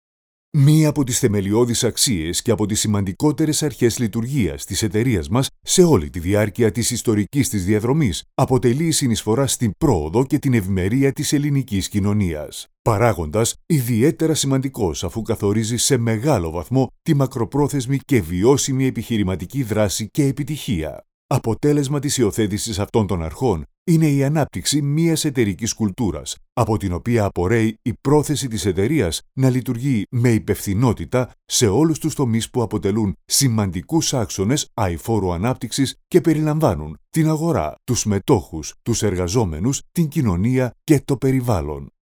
Greek Voice Over artist, radio presenter, TV production and copywriter
Sprechprobe: Industrie (Muttersprache):
AKG c214 M-Audio sound card